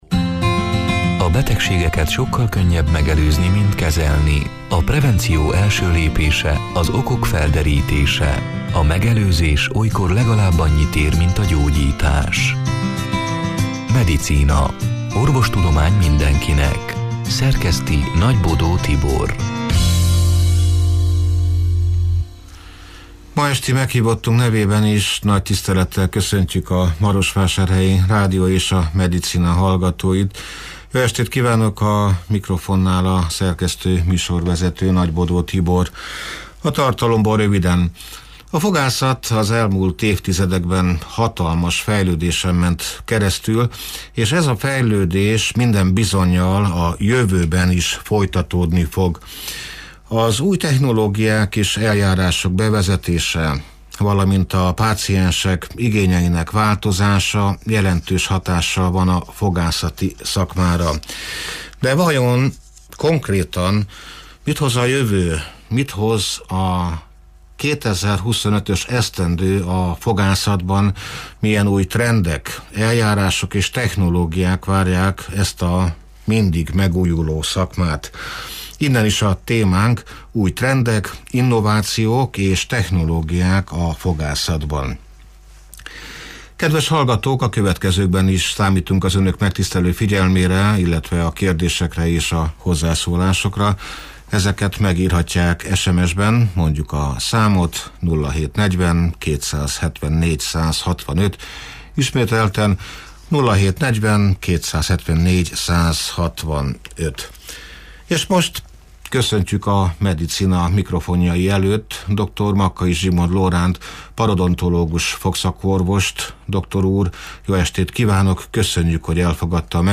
(elhangzott: 2025. január 8-án, szerdán este nyolc órától élőben)